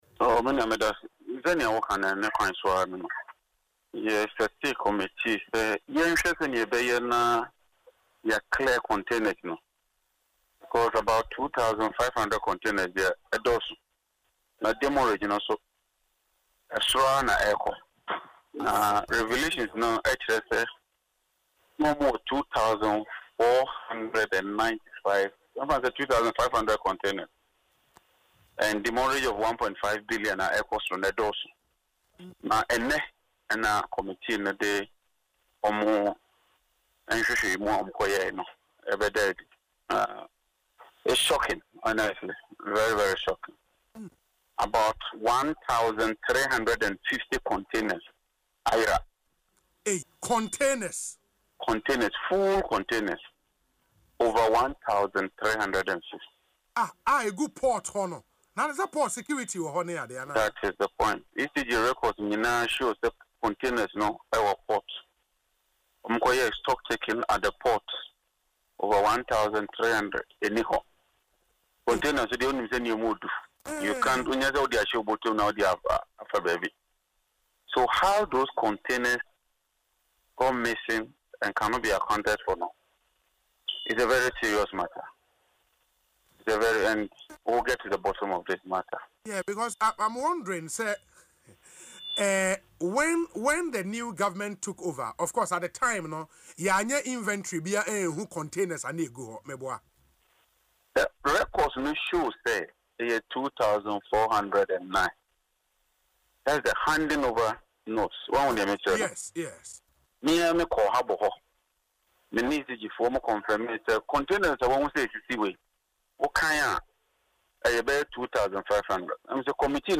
Reacting to the report in an interview on Asempa FM’s Ekosii Sen, Mr. Jinapor confirmed the issue, stating, “One container can be worth about $500,000, and now we are being told that over 1,350 containers have vanished from the port. This is shocking.”